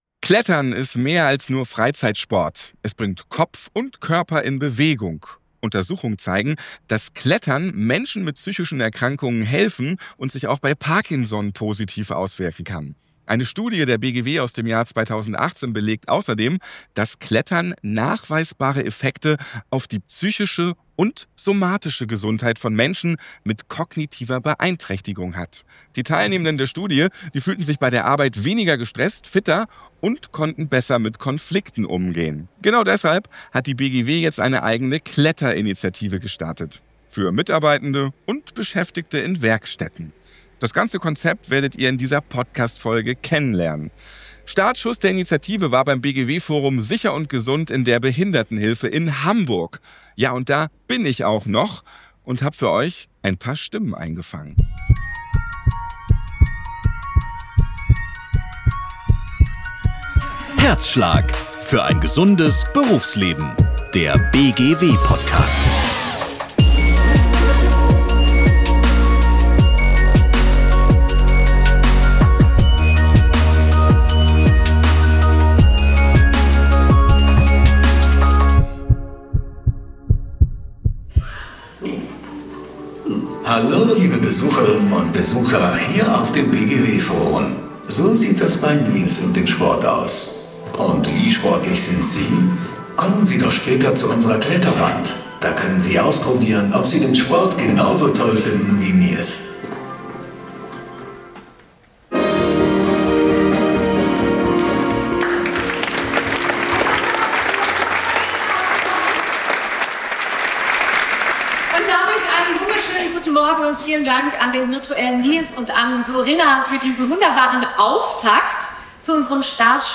Dort spricht er mit Sportlerinnen und Sportlern über die Bedeutung von Inklusion im Sport, über persönliche Erfolgserlebnisse und darüber, wie Klettern Lebensqualität, Selbstwirksamkeit und Teilhabe stärken kann.